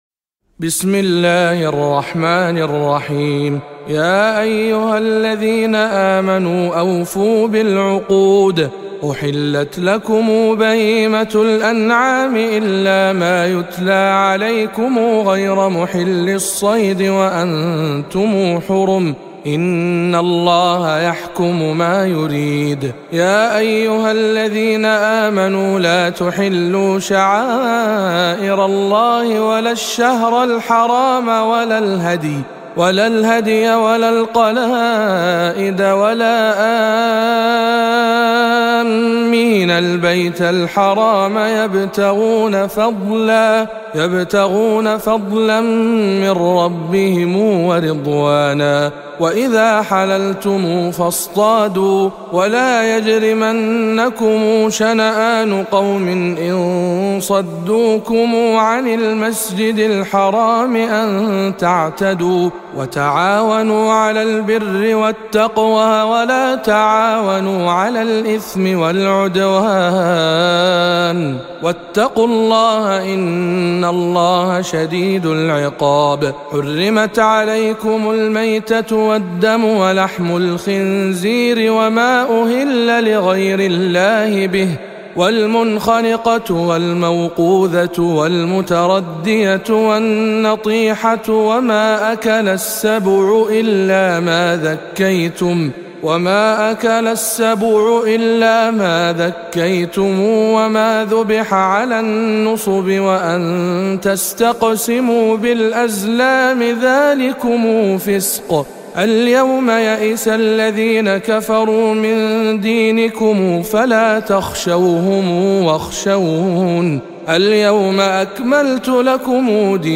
005. سورة المائدة برواية قنبل عن ابن كثير